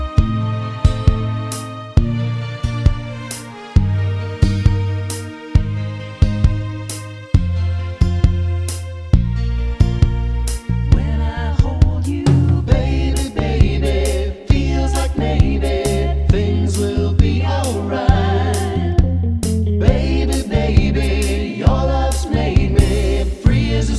Minus Rhodes Piano Pop (1970s) 4:03 Buy £1.50